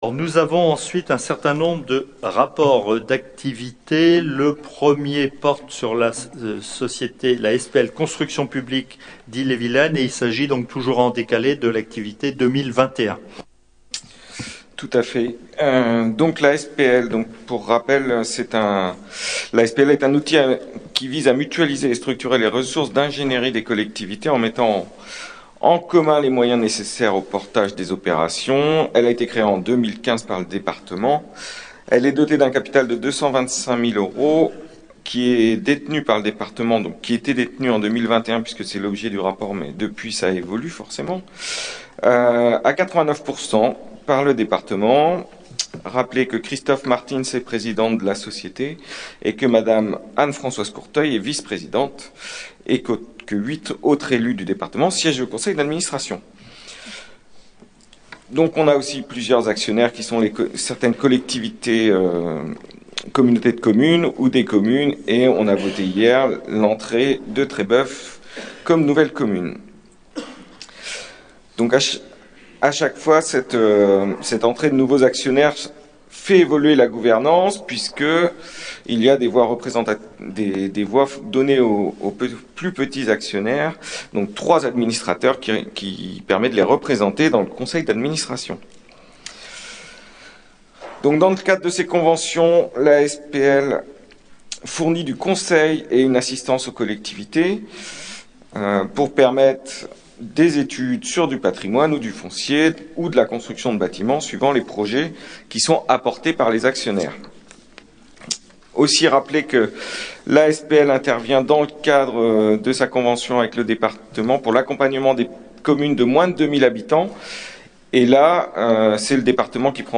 • Assemblée départementale du 17/11/22